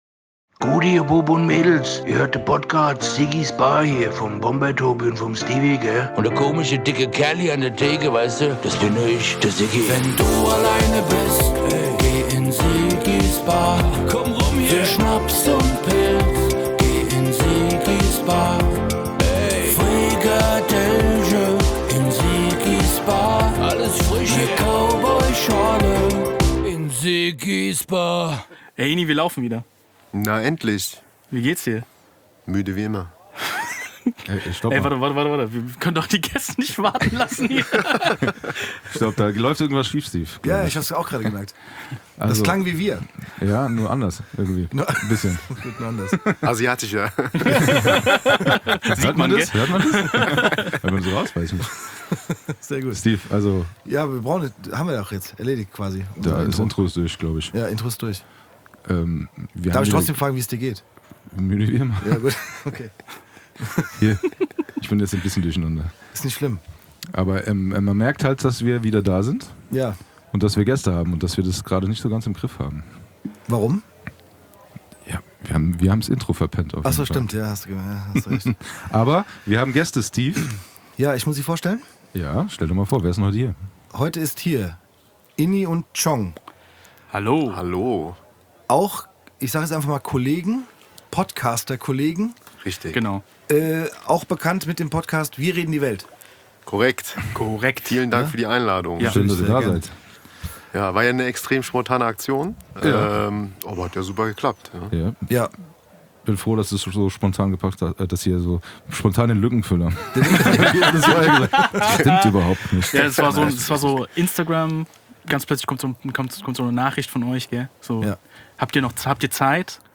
Da haben die Vier sich einiges zu erzählen.